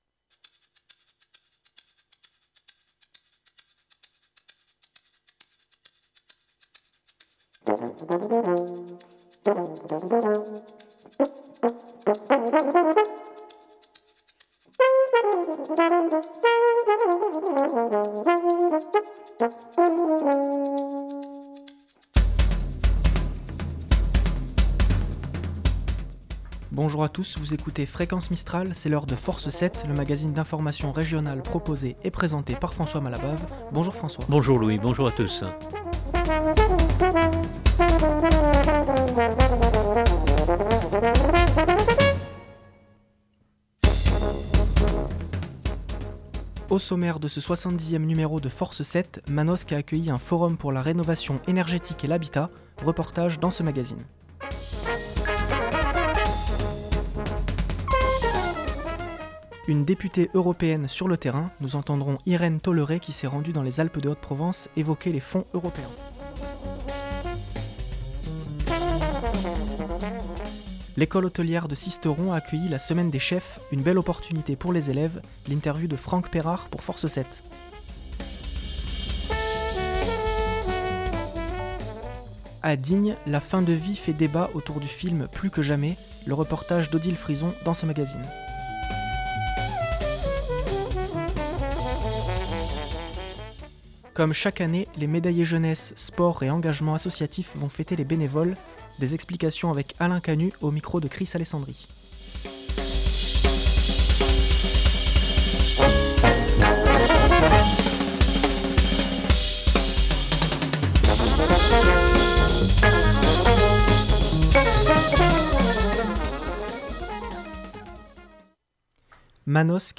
-Manosque a accueilli un forum pour la rénovation énergétique et l’habitat. Reportage dans ce magazine.
Nous entendrons Irène Tolleret qui s’est rendue dans les Alpes de Haute-Provence évoquer les fonds européens.